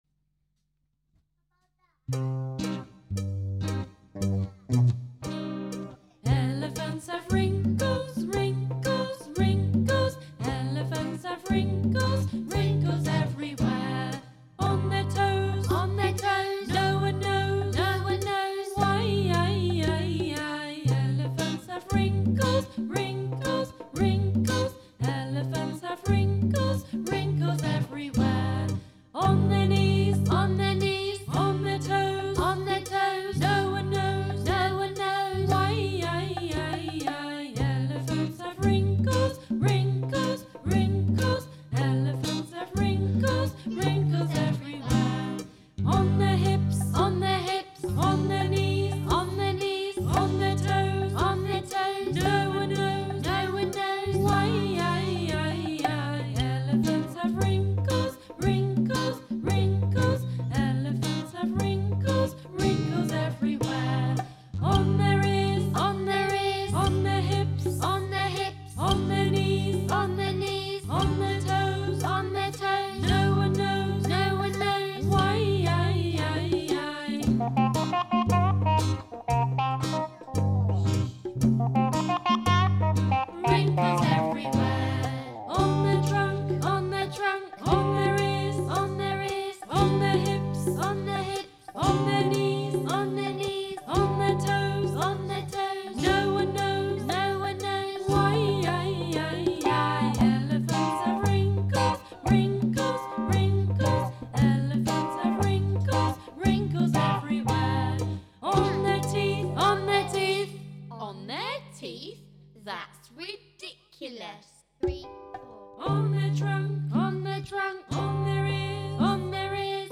Super simple songs and rhymes for babies and young children.